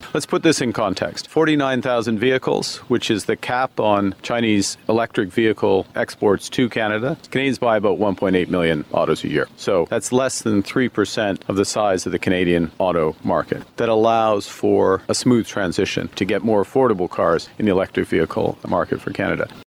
Audio with Canadian Prime Minister Mark Carney, Saskatchewan Premier Scott Moe, and U.S. President Donald Trump.